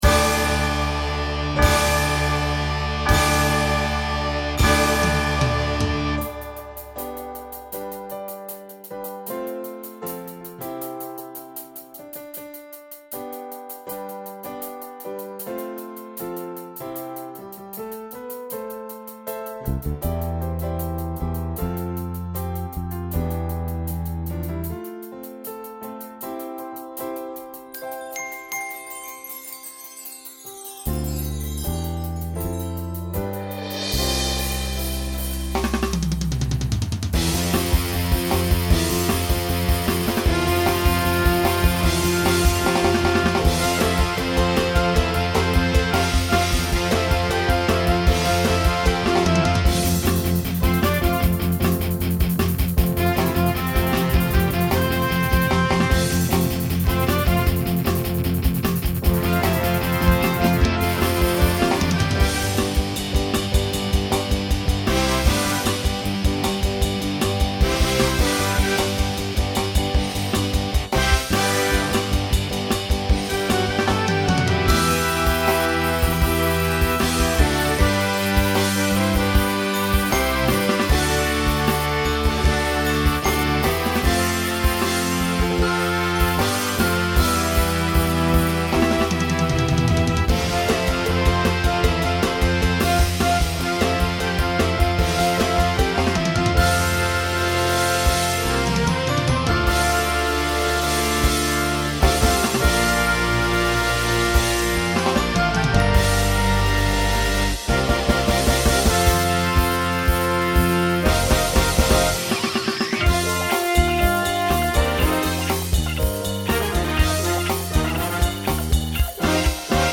Voicing Mixed Instrumental combo Genre Pop/Dance , Rock